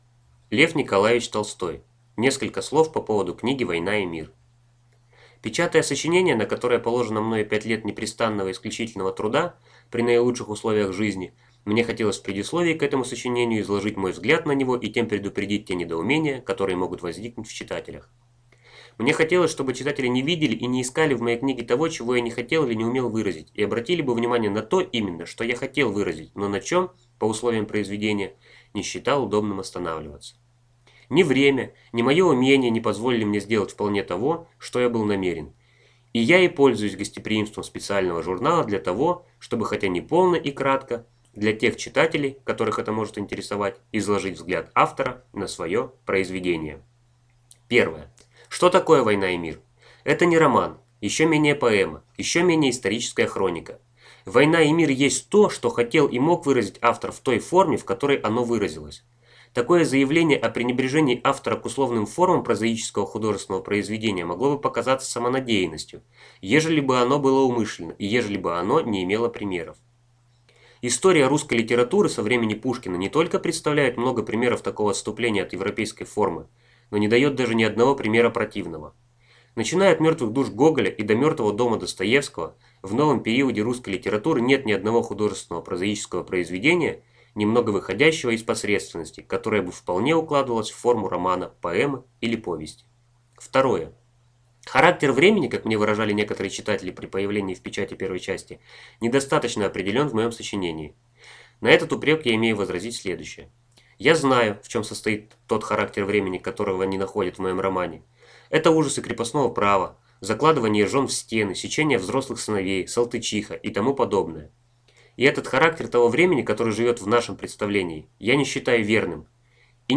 Аудиокнига Несколько слов по поводу книги «Война и мир» | Библиотека аудиокниг